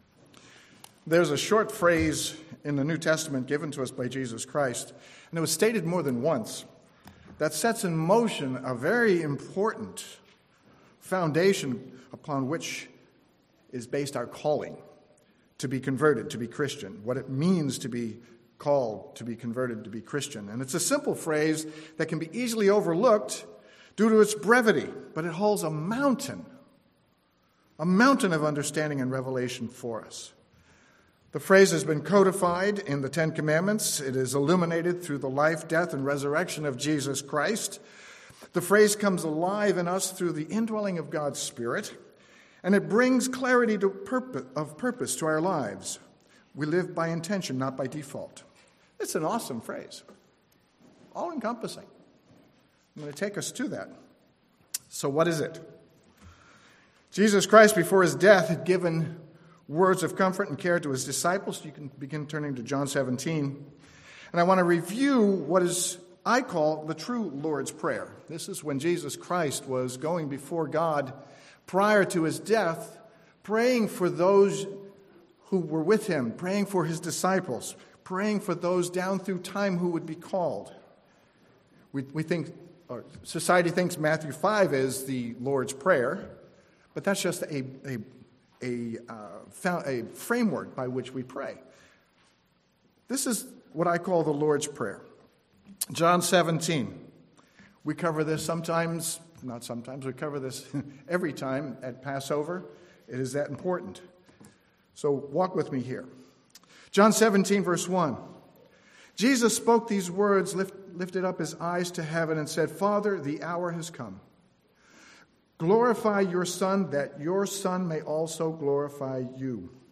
This sermon examines the deep meaning of what being one with God means.